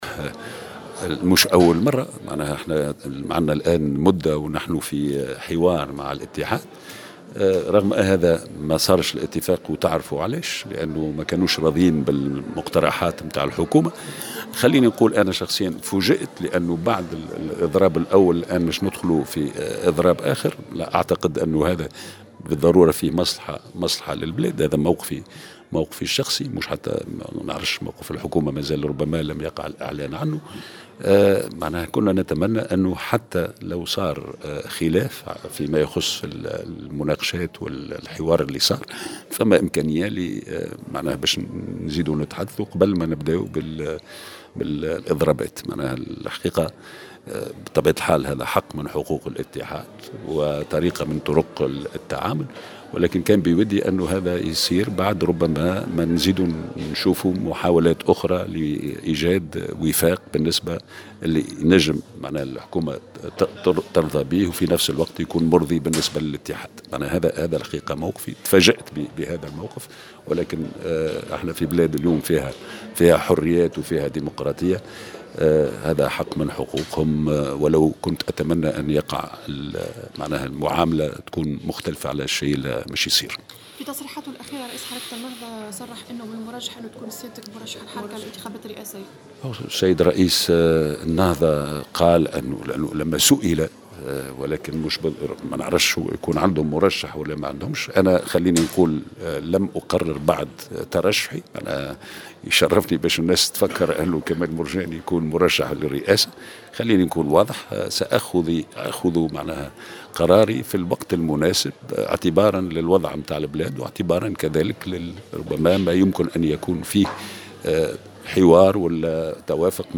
وفي تصريح لموفدة "الجوهرة أف أم" حول نيّته الترشّح للانتخابات الرئاسية من عدمه على هامش اشرافه على ندوة نظمها حزب المبادرة في سوسة، أكد كمال مرجان أنه سيتخذ هذا القرار في الوقت المناسب، مشيرا الى أخذه بعين الاعتبار كل مساندة له.